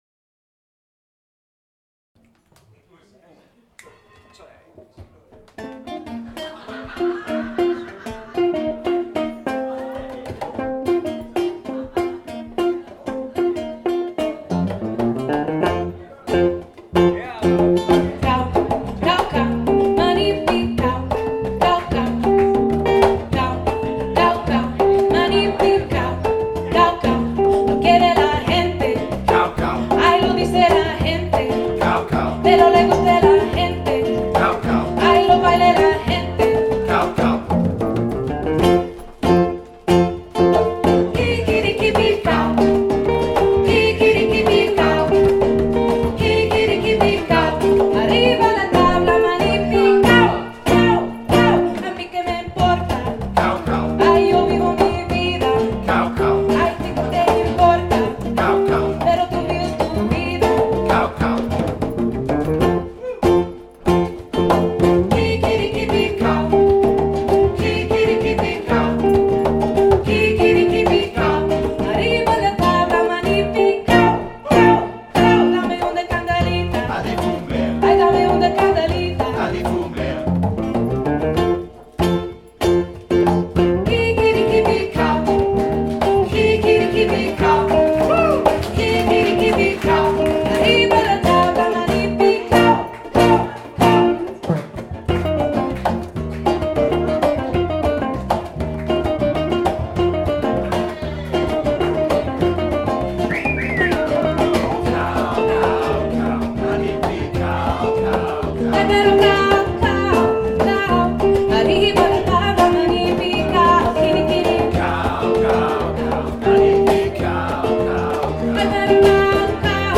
energisk og dansevenlig musik fra over alt i latin Amerika
• Salsa/reggae/latin